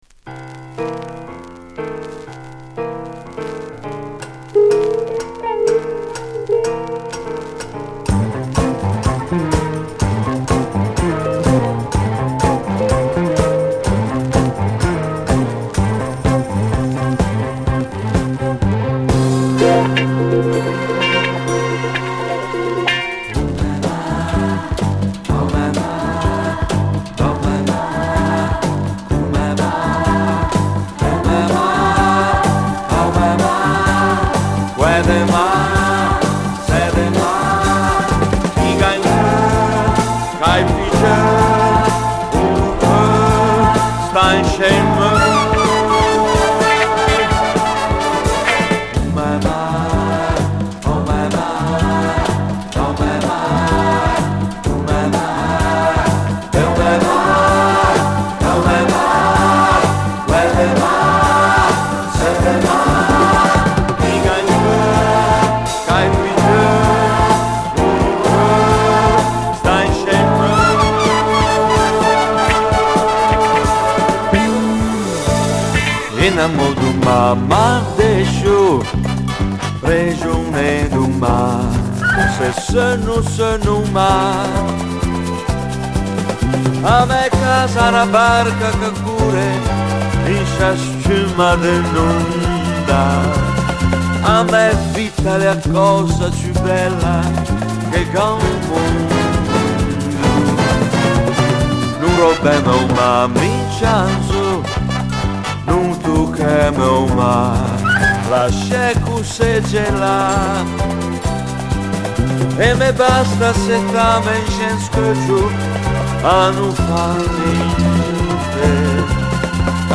両面ともラテン・ブラジリアン・テイストを散りばめた、ひとクセあるジャズ・グルーヴになっています。
※全体的にチリ音が出ます（試聴にてご確認ください）。